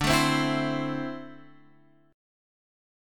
D7sus2 chord